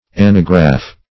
Search Result for " anagraph" : The Collaborative International Dictionary of English v.0.48: Anagraph \An"a*graph\ ([a^]n"[.a]*gr[.a]f), n. [Gr.